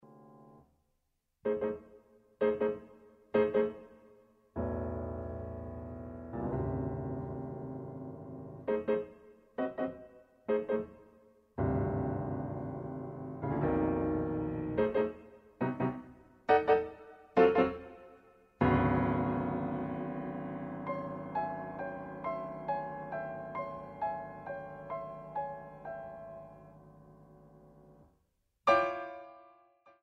a Steinway mod.D 274 piano.